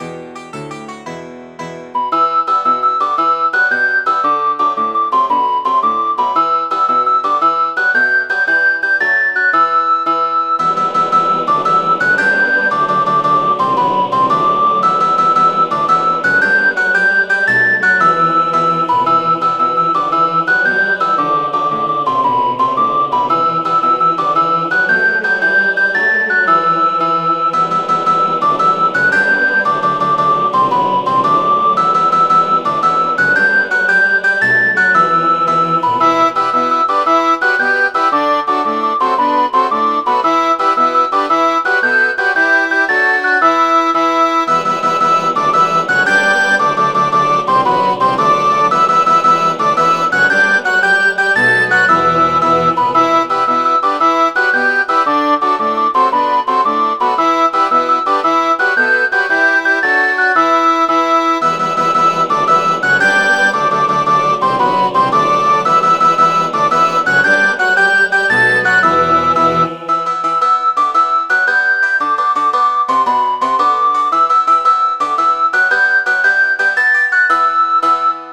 Midi File, Lyrics and Information to Paddy Works on the Erie